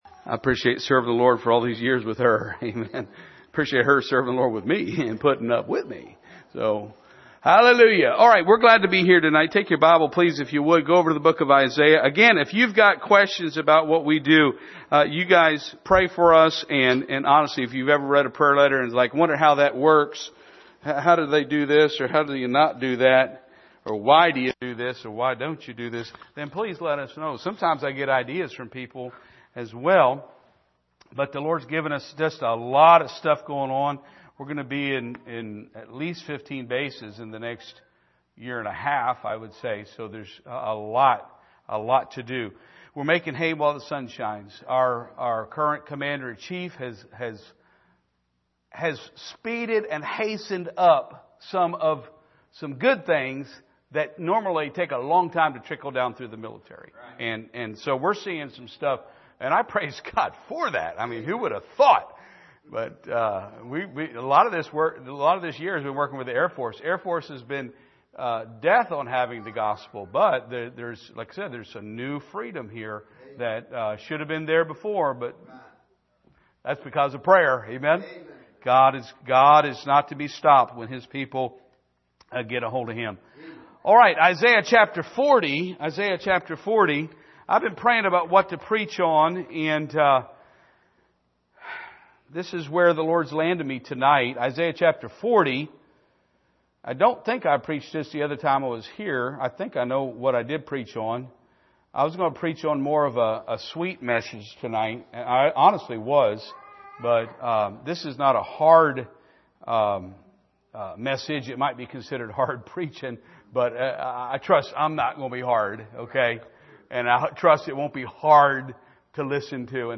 Isaiah 40:1-8 Service: Sunday Evening Gaining Power Over The Flesh « Quenching The Thirsty Soul